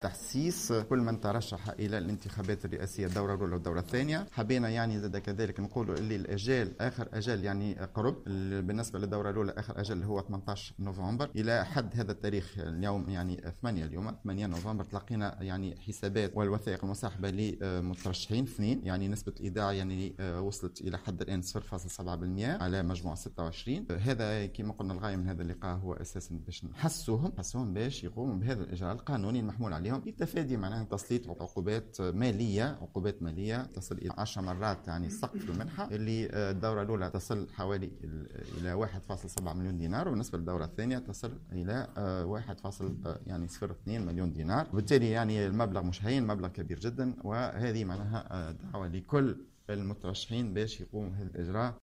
دعا نجيب القطاري الرئيس الأول بدائرة المحاسبات في تصريح لمراسلة الجوهرة "اف ام" اليوم الجمعة كافة المترشحين للانتخابات الرئاسية بدوريها الأول والثاني إلى ضرورة الإسراع بإيداع الحسابات و الوثائق بتمويل حملاتهم الانتخابية قبل انقضاء الآجال المحددة ب 18 نوفمبر الجاري.